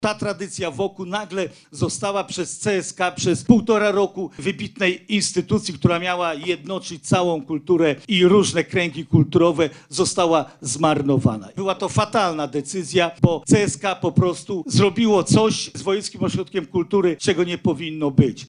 Podczas dzisiejszej sesji sejmiku województwa przyznał jednak, że inkorporacja WOk-u i CSK okazała się porażką.
• mówi Jarosław Stawiarski, marszałek województwa.